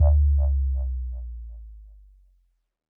Bass Power Off 2.wav